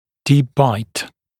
[diːp baɪt][ди:п байт]глубокий прикус; глубокое резцовое перекрытие